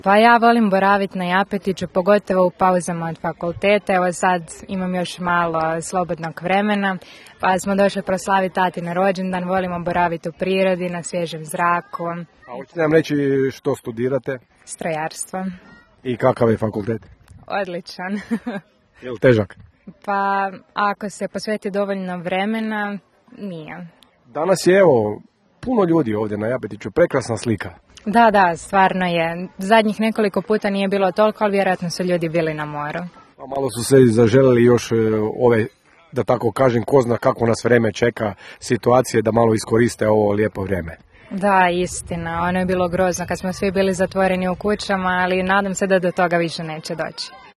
Bili smo na ‘Japu’ i snimili dojmove posjetitelja